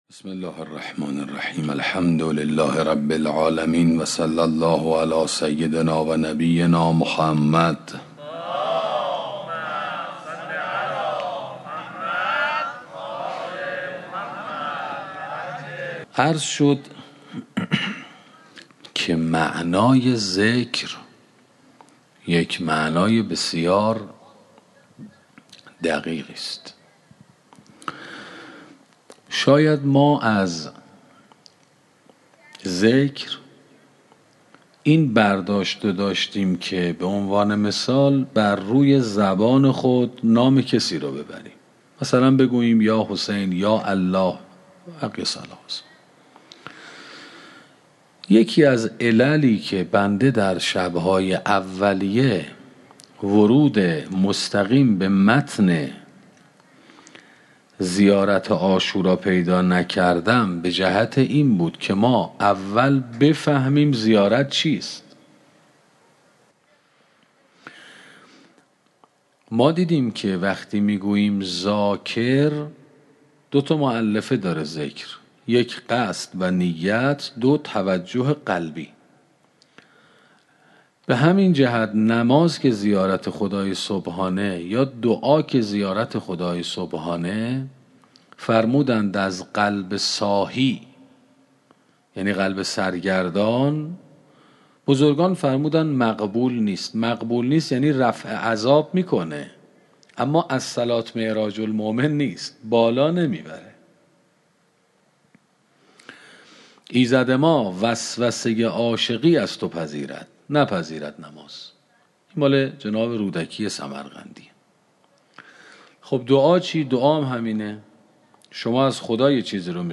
سخنرانی شرح زیارت عاشورا 15 - موسسه مودت